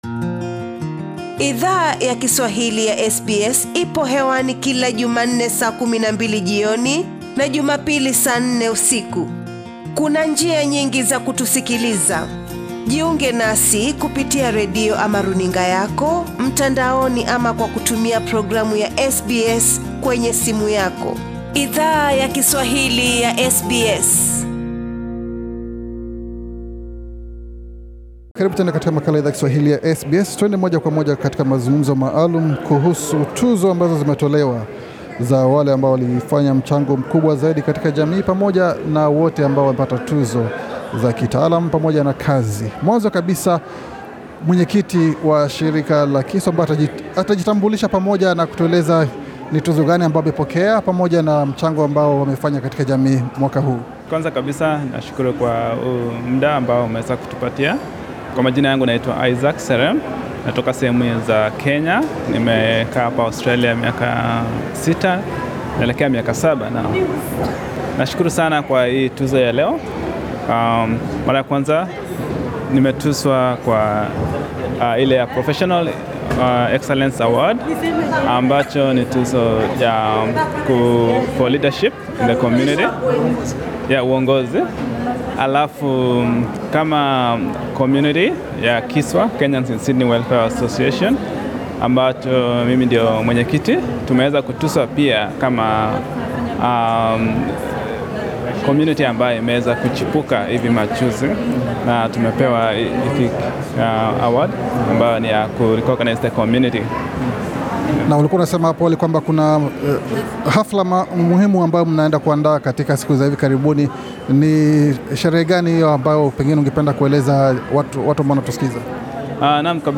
Shirika mpya la Kenyans in Sydney Welfare Association maarufu kwa ufupi kama (KISWA), havi karibuni lili tambuliwa kwa huduma bora katika jamii, katika sherehe iliyo andaliwa na tawi la NSW la shirika la Celebration of African Australians. Idhaa ya Kiswahili ya SBS ilizungumza na baadhi ya wanachama wa KISWA, punde baada ya sherehe hiyo kukamilika.